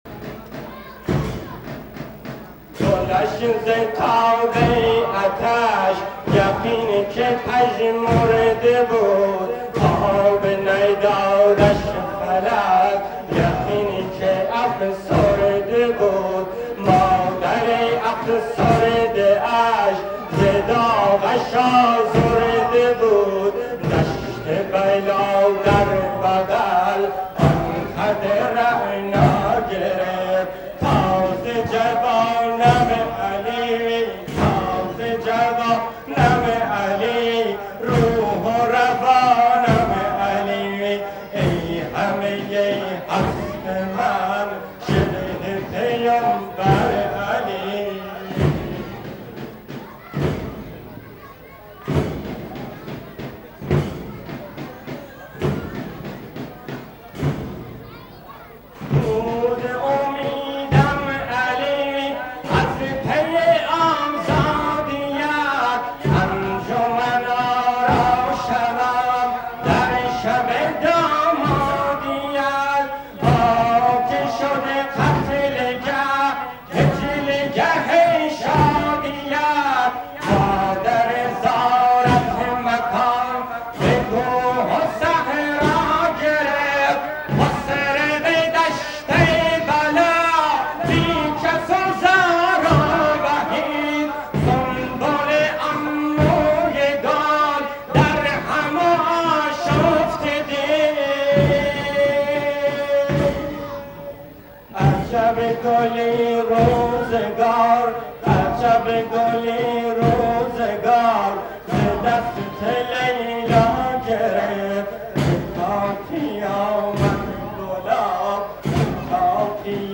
نوحه زنجیرزنی حضرت علی اکبر (ع) - عجب گلی روزگار ز دست لیلا گرفت